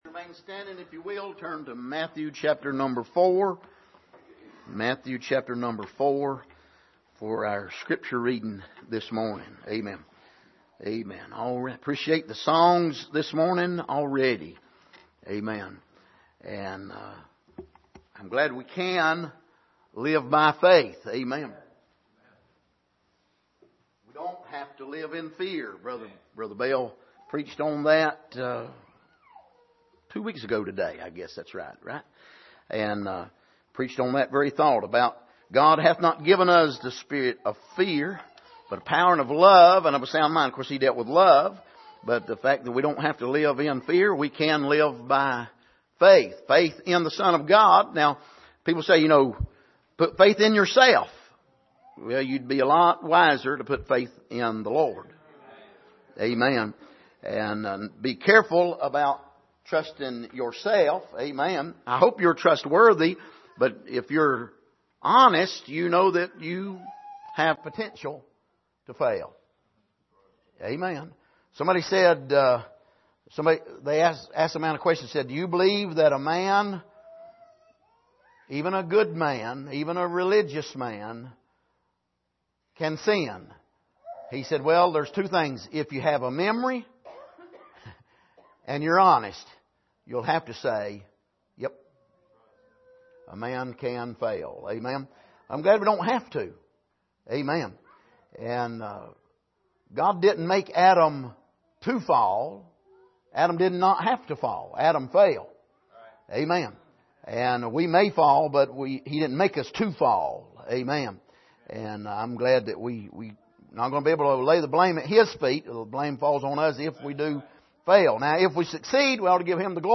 Passage: Matthew 4:1-11 Service: Sunday Morning